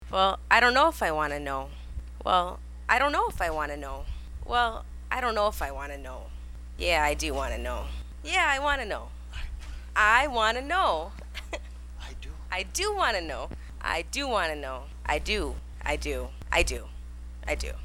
This is a raw audio clip of several takes I recorded.